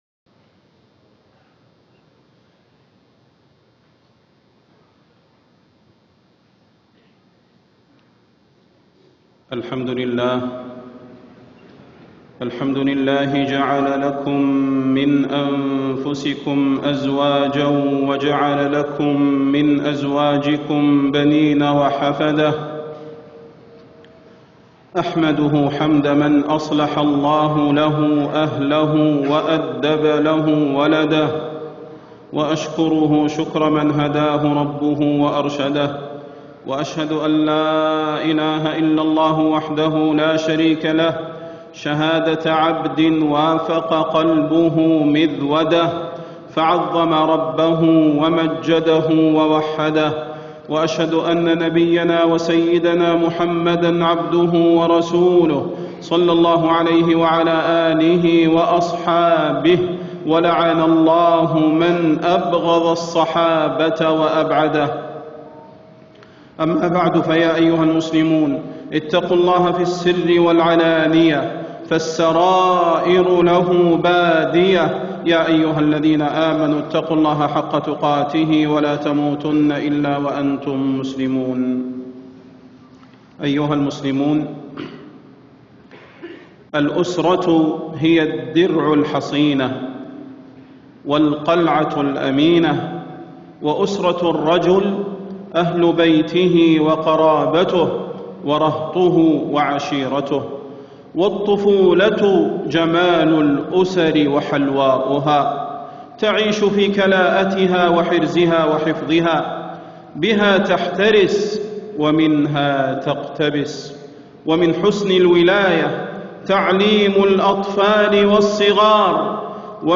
خطبة الجمعة 13 شعبان 1437هـ > خطب الحرم النبوي عام 1437 🕌 > خطب الحرم النبوي 🕌 > المزيد - تلاوات الحرمين